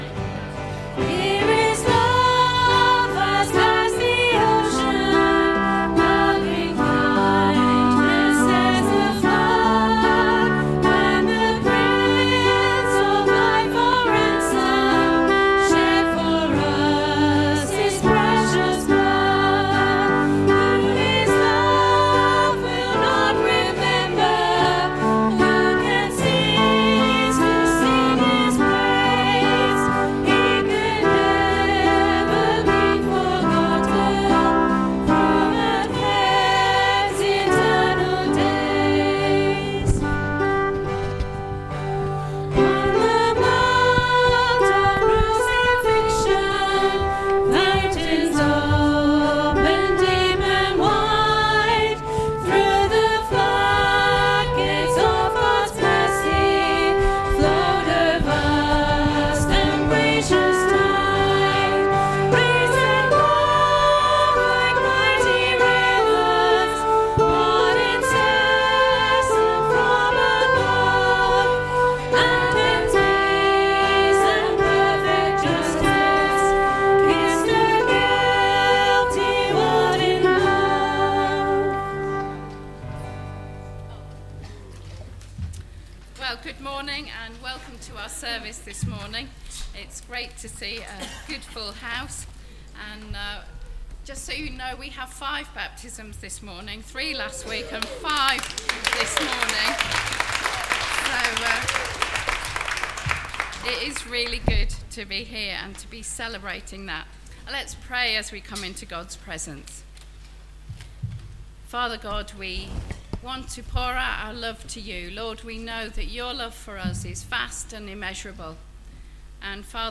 Service Audio
This service includes baptisms